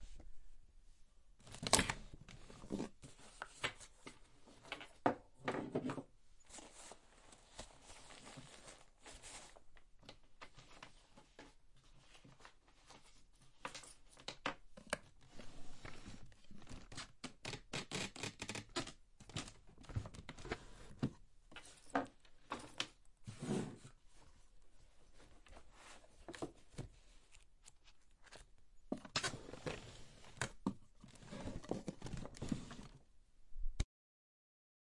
废弃的工厂金属后世界末日的回声 " 翻阅抽屉1
描述：记录在都柏林的一家废弃工厂。
Tag: 工业 工厂 金属 碰撞 噪声